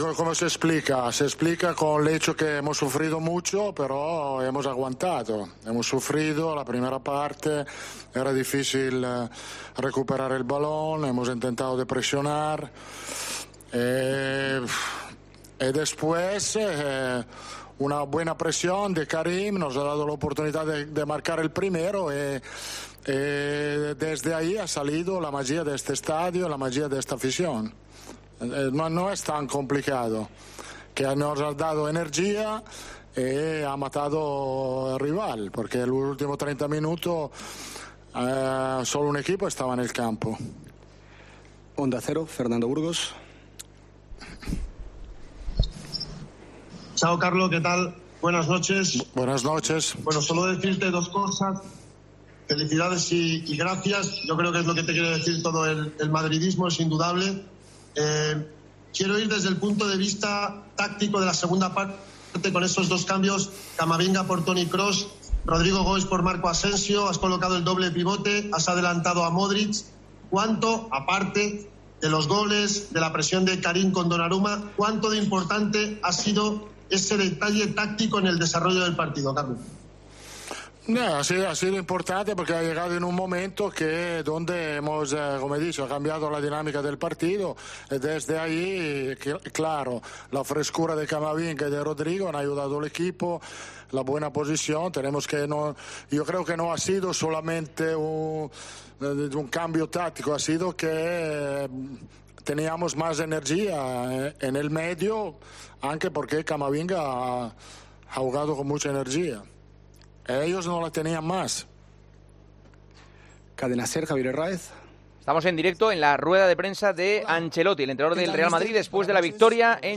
El técnico madridista ha hablado en Movistar tras la victoria del Real Madrid ante el PSG.